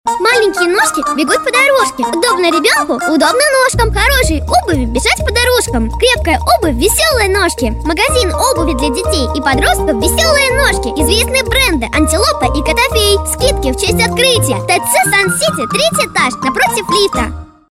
ролик для детского магазина. мой продакшн.